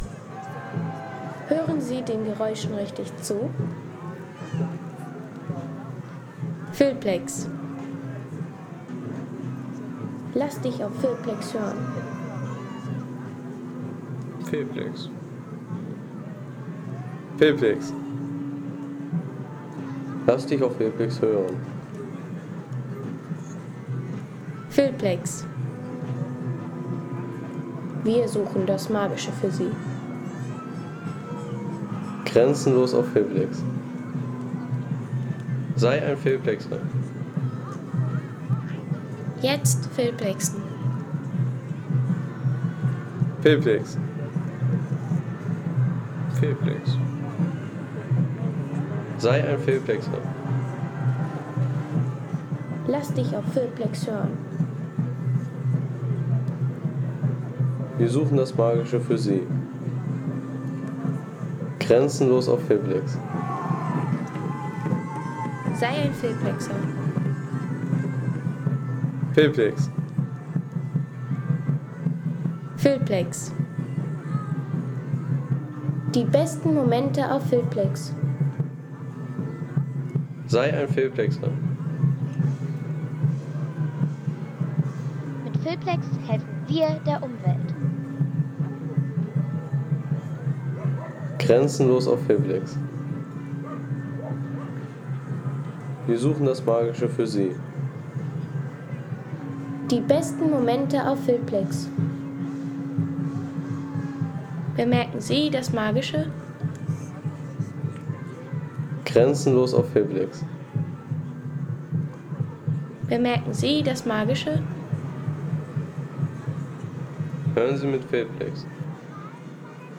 Mittelalterlicher Festzug